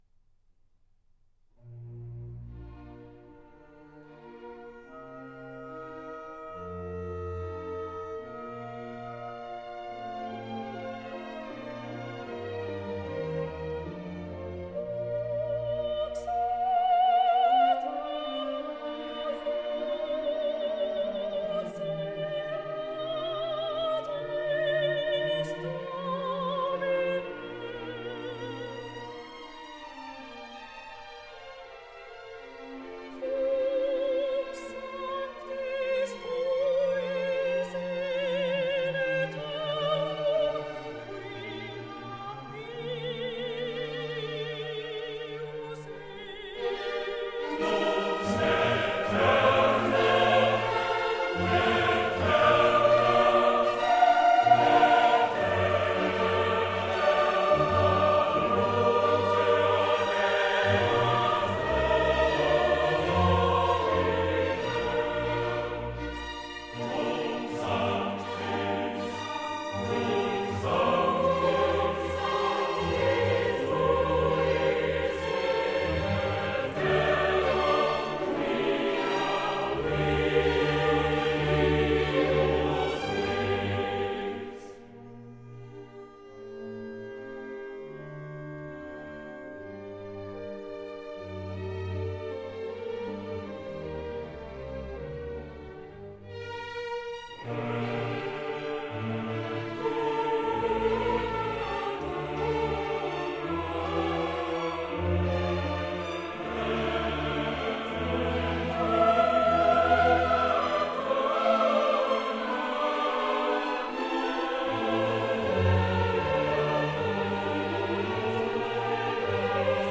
Choir
A musical ensemble of singers.